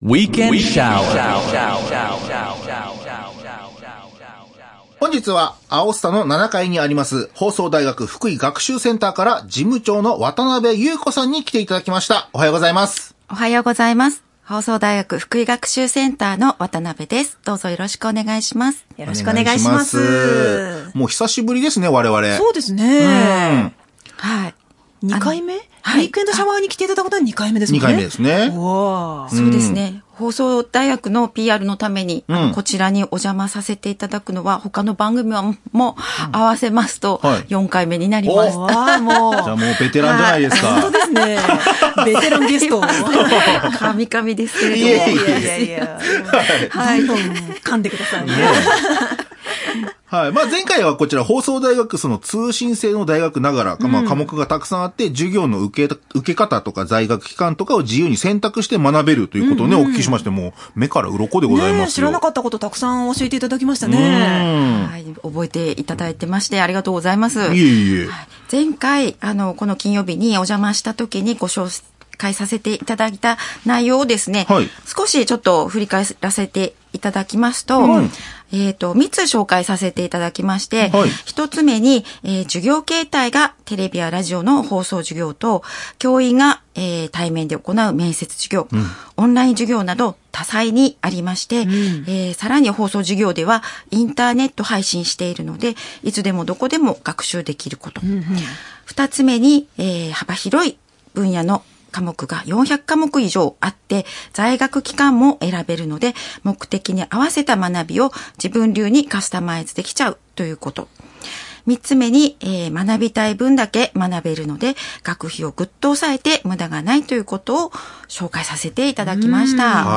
令和8年1月16日（金）FM福井にて2026年度4月入学生募集の案内を放送しました。放送大学の数々のメリットをインタビュー形式でわかりやすく案内しています。